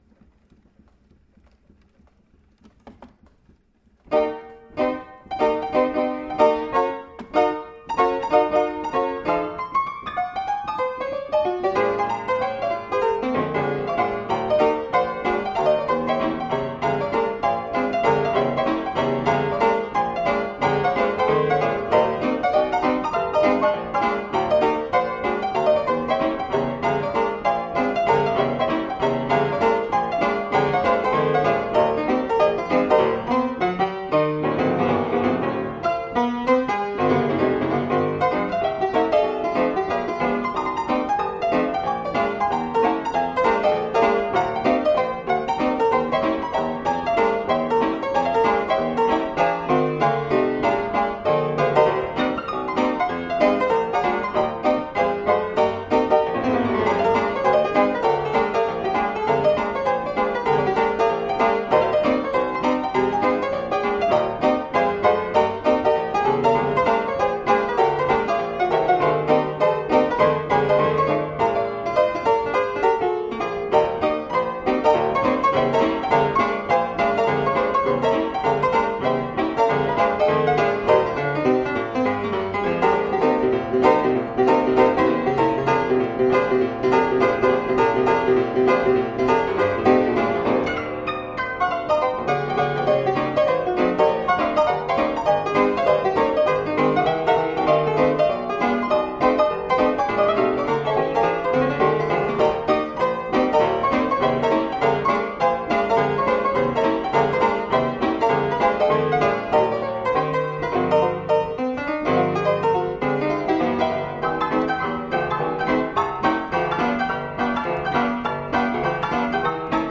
Pianola Push-Up
Véritable Robot pianiste, il suffisait de le poser sur un piano traditionnel pour que celui-ci joue tout seul.
Une nourrice se gonfle d'air par action du pédalier et c'est la présence du rouleau de papier perforé qui permet aux marteaux de s'abattre sur les touches du piano.
Musée de Musique Mécanique